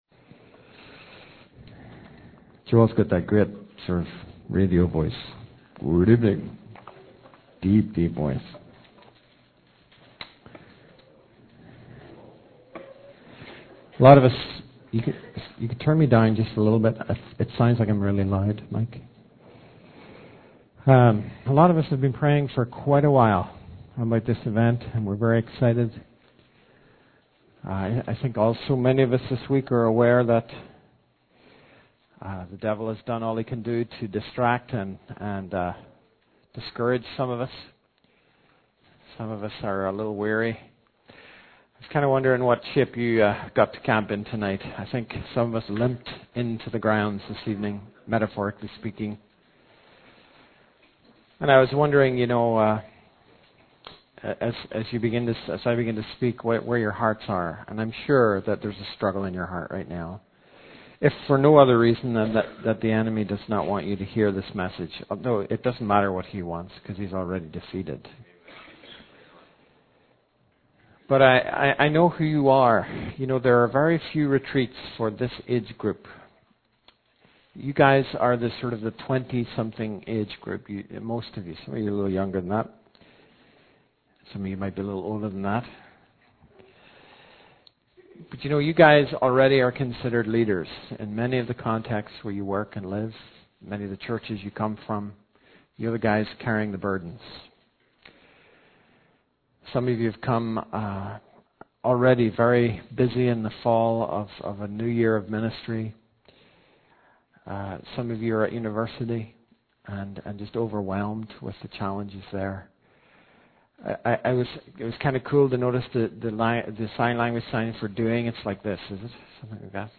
In this sermon, the speaker shares a story about a holy man who holds a young surcher under water until he nearly drowns.